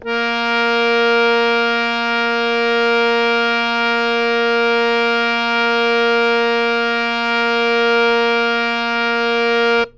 harmonium
As3.wav